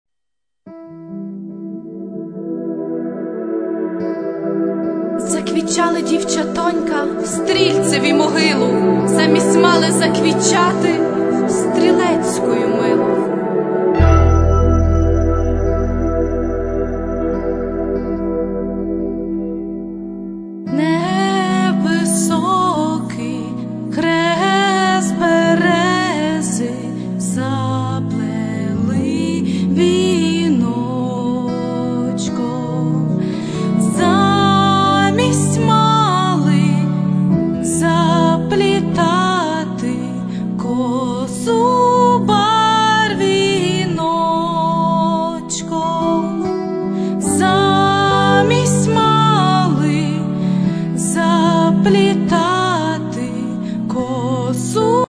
Children Perform Ukrainian Folk And Patriotic Songs